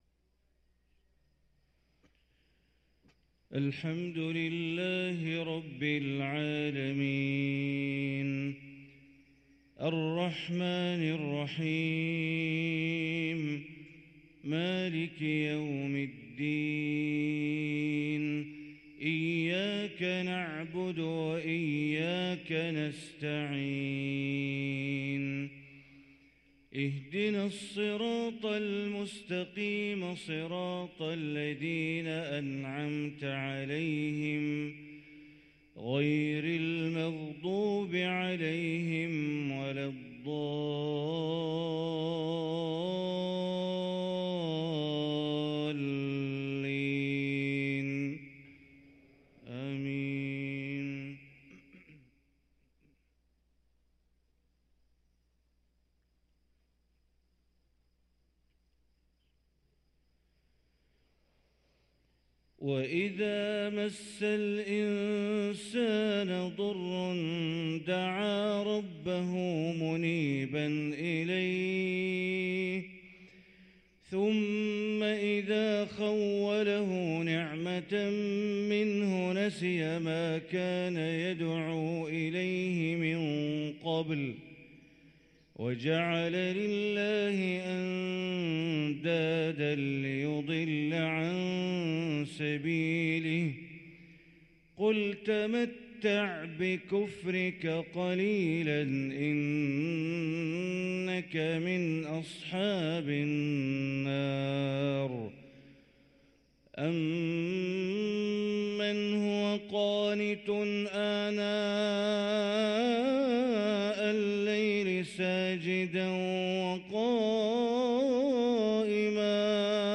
صلاة الفجر للقارئ بندر بليلة 20 رجب 1444 هـ
تِلَاوَات الْحَرَمَيْن .